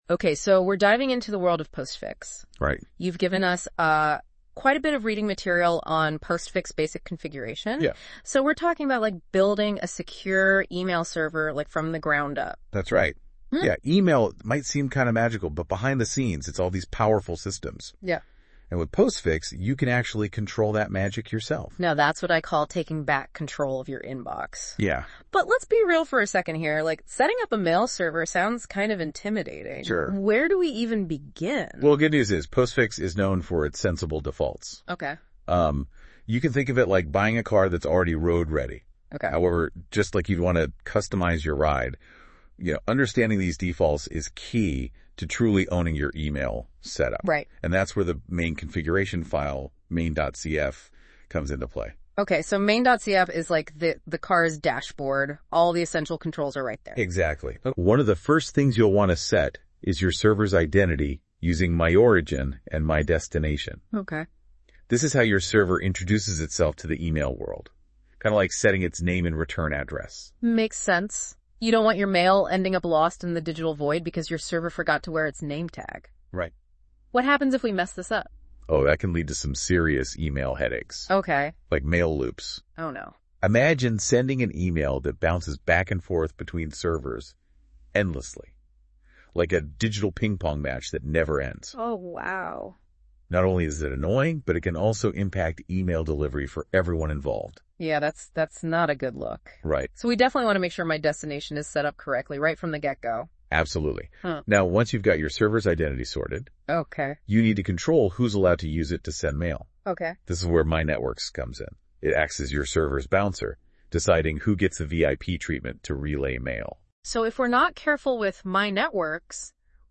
I suppose that the AI has a rich choice of cliches to make the conversation flow.